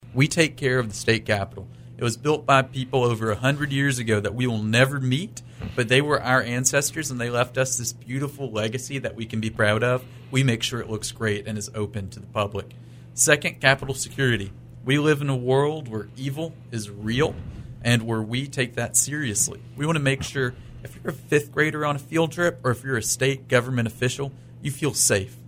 During an interview, Jester offered insight into the wide-ranging duties of the Secretary of State’s Office, including maintaining the State Capitol building and ensuring its security, supporting elections, overseeing business filings, and promoting transparency through public records access.